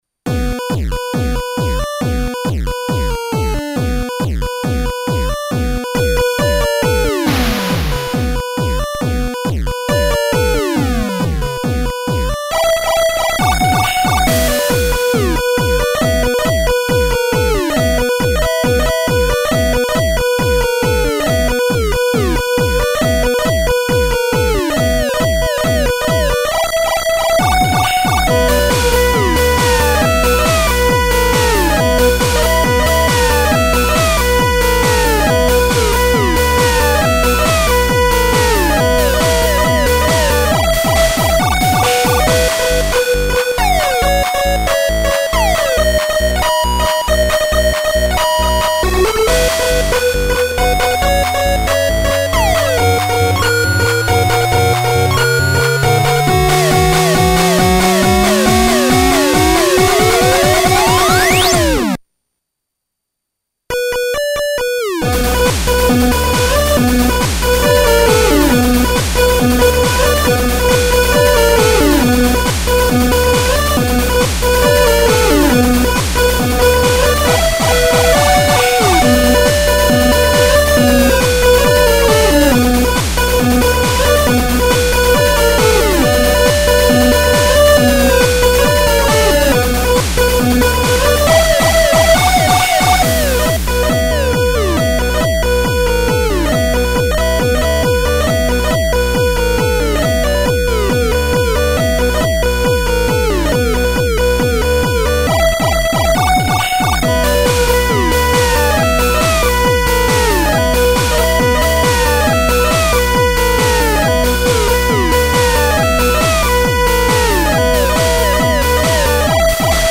Chiptune version